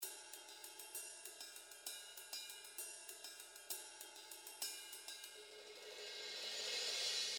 98 BPM - Waltz melody (24 variations)
Waltz rhythm in 6/8 time signature.
The drum loops in this package are in 98 BPM.
There are in total 24 loops + 3 cymbals loops.